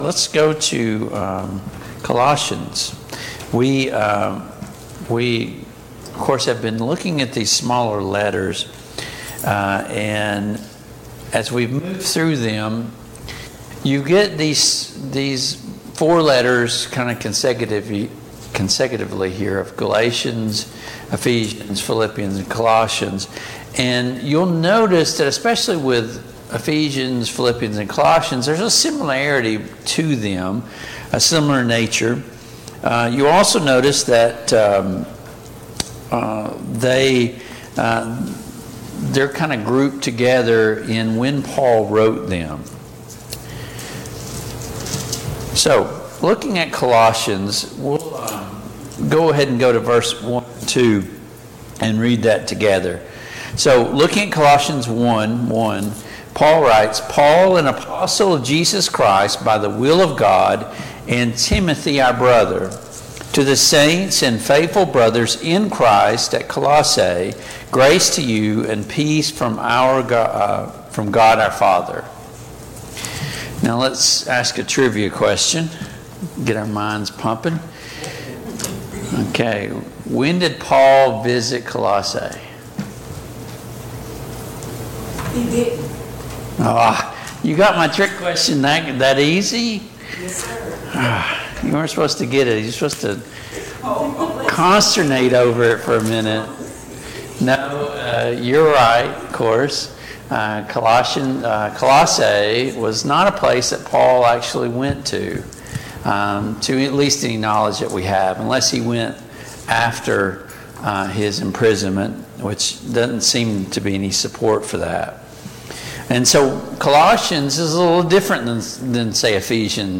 Passage: Colossians 1:1-14 Service Type: Mid-Week Bible Study Download Files Notes « 36.